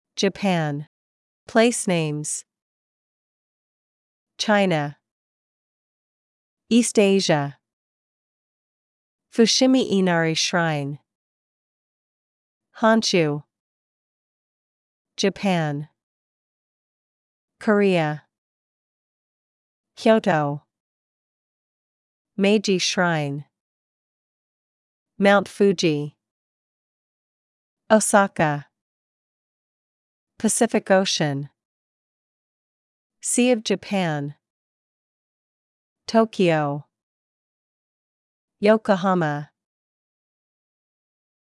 JAPAN: Place Names
• a pronunciation guide to key place names